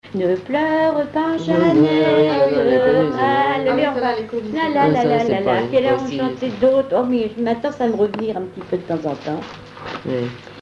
Mémoires et Patrimoines vivants - RaddO est une base de données d'archives iconographiques et sonores.
Chansons et commentaires
Pièce musicale inédite